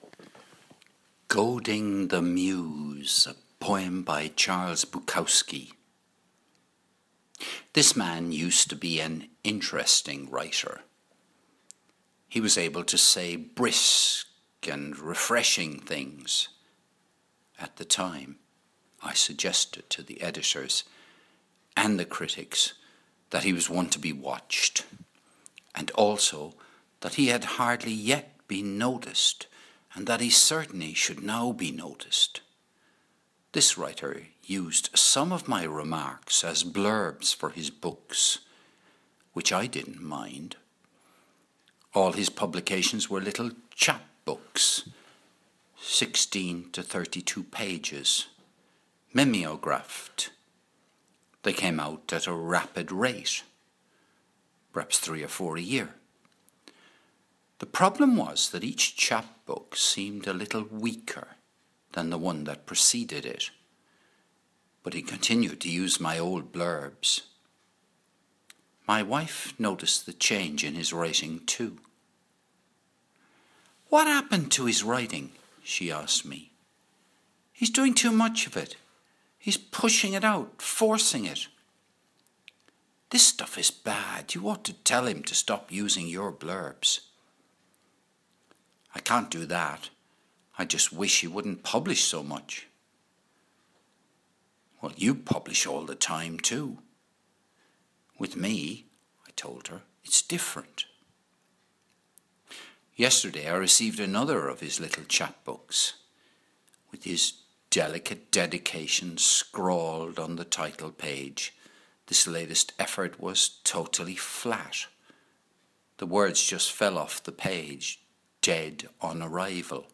"Goading The Muse" - Poem by Charles Bukowski